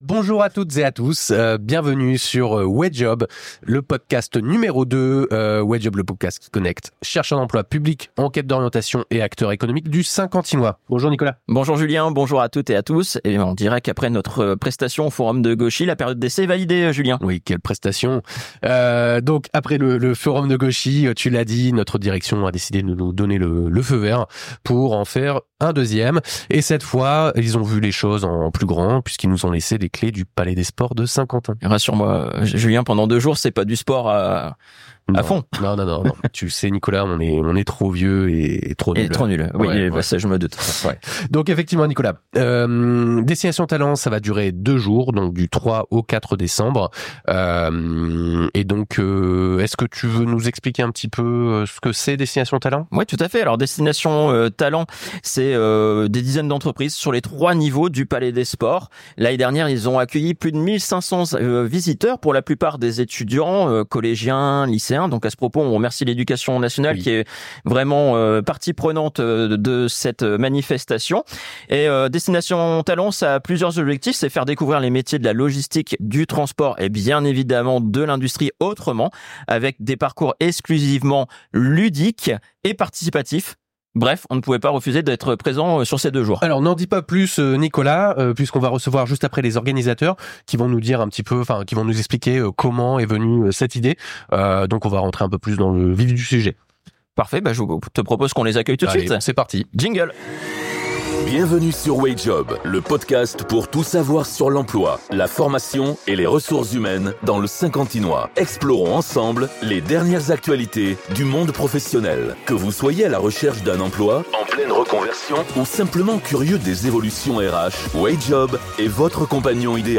Episode 14 - Table Ronde des Organisateurs : Connecter les Jeunes et l'Industrie